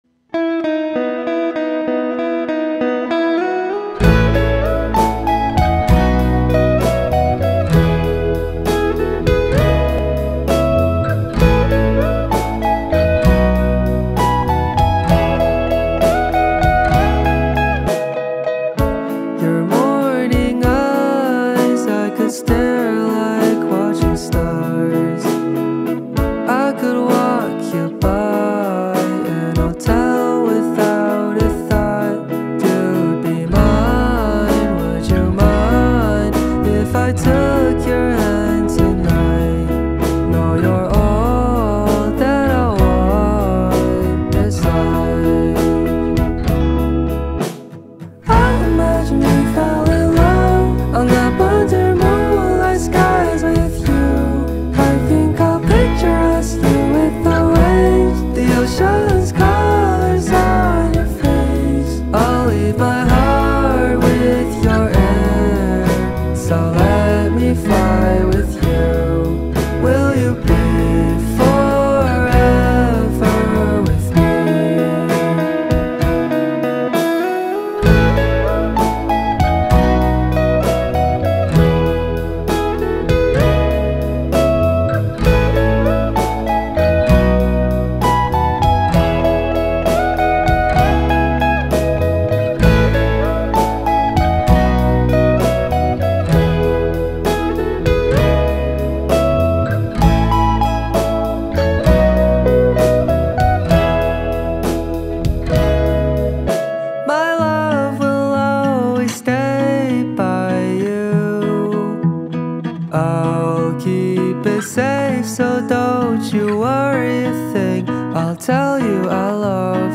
ملودی آروم